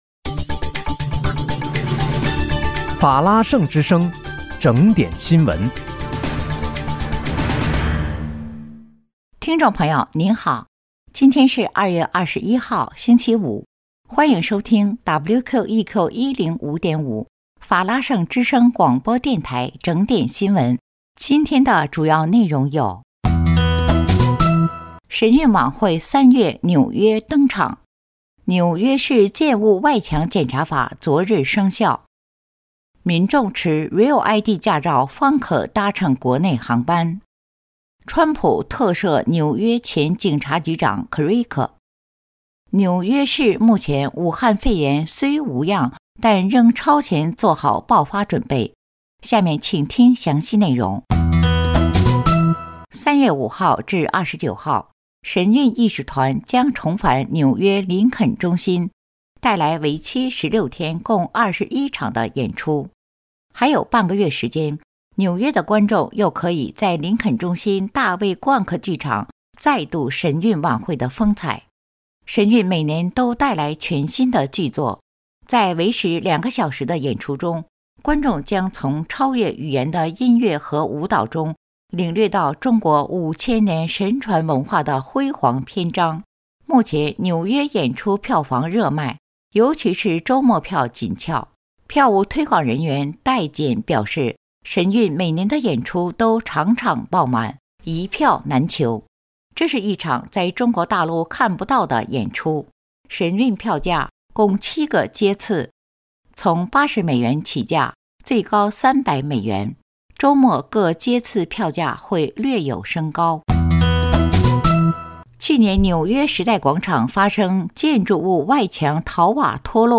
2月21日（星期五）纽约整点新闻